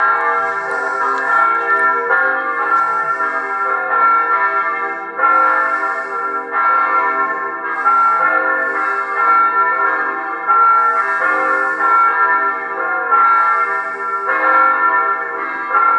Bells ringing for new pope at Sacred Heart. Hanley!!